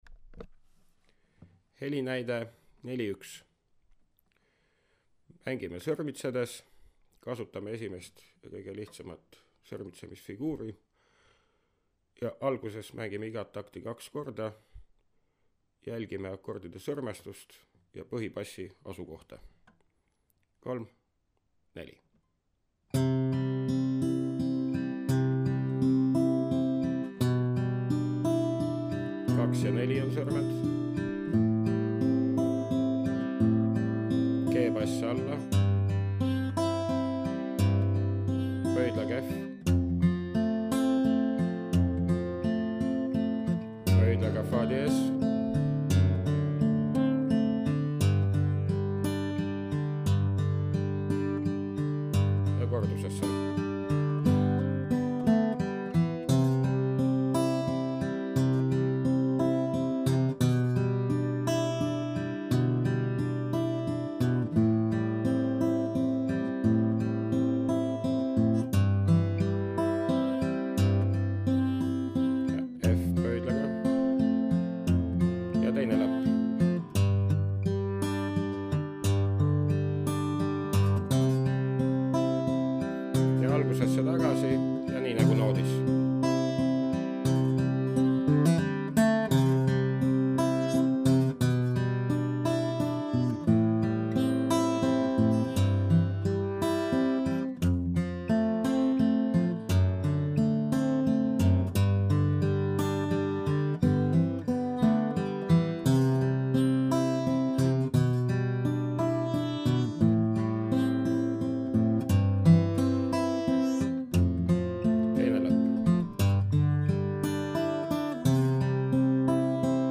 Teeme endale allpool toodud järgnevuse selgeks sõrmitsedes, kasutades selleks näiteks lihtsat arpedžo võtet  ¾ taktimõõdus sõrmestusega p-i-m-a-m-i, kus iga sõrm mängib oma keelel – näiteks i-sõrm 3. keelel, m-sõrm 2. keelel, a-sõrm 1. keelel, vaid pöial peab valima keele, kus paikneb meile vajalik noot, mis on akorditabelites tähistatud ringiga.
Tüüpjärgnevus C-duuris: